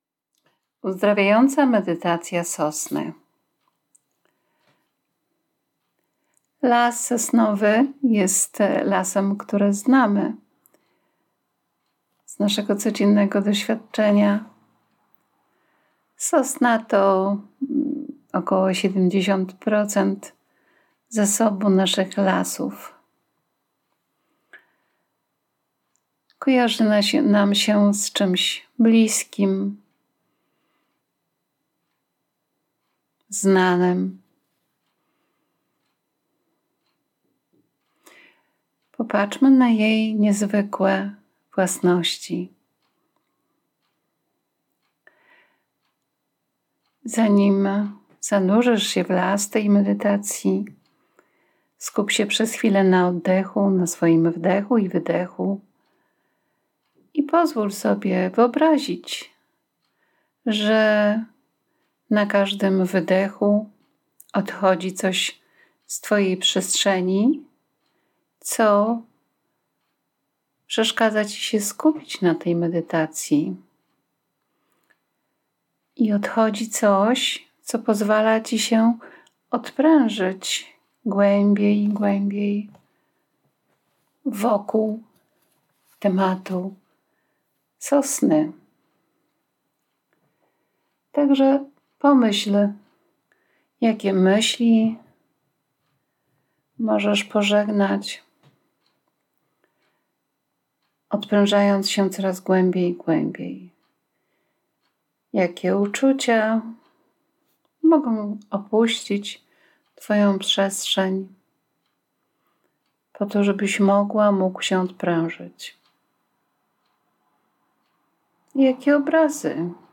Uzdrawiająca medytacja sosny to bardzo głęboko rozluźniająca medytacja, która pomaga rozluźnić się po całodniowym wysiłku.